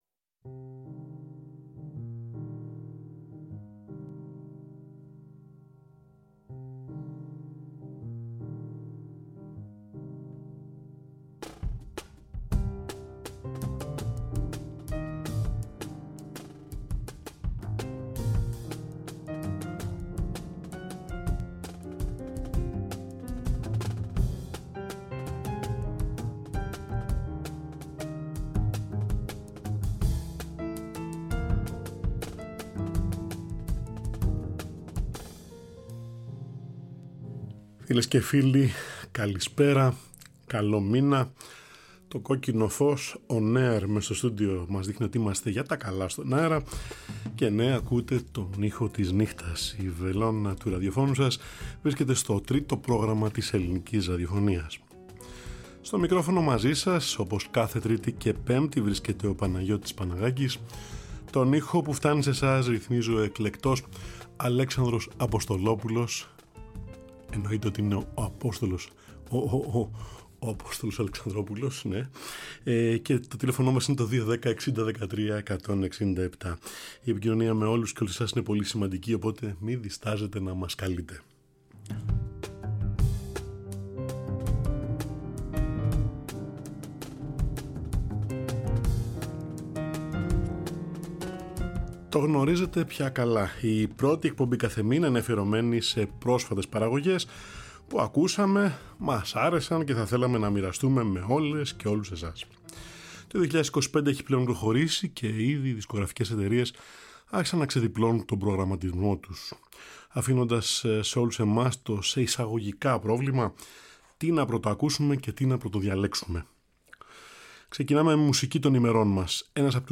jazz-soul-rock
πιάνο
μελαγχολικά και μελωδικά Σκανδιναβικά μονοπάτια